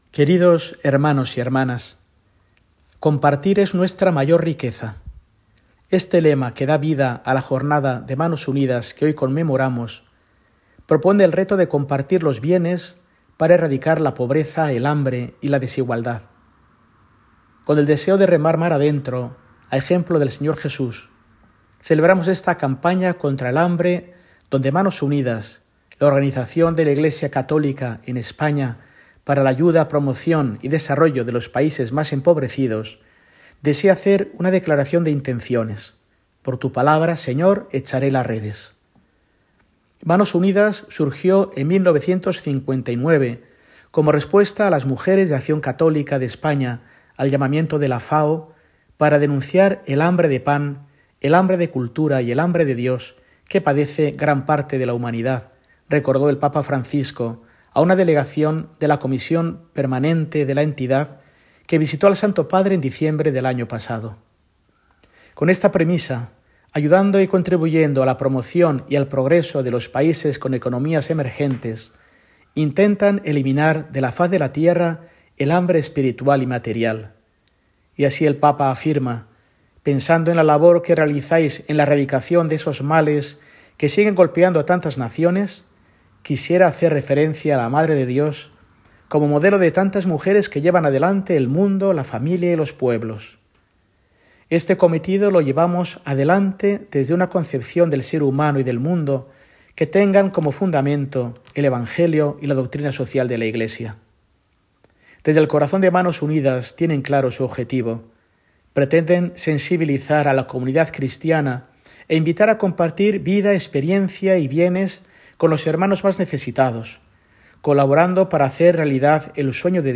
Mensaje semanal de Mons. Mario Iceta Gavicagogeascoa, arzobispo de Burgos, para el domingo, 9 de febrero de 2025, V del Tiempo Ordinario